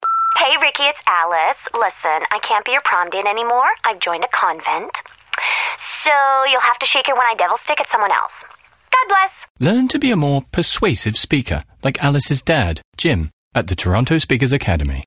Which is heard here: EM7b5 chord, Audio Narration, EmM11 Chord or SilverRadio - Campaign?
SilverRadio - Campaign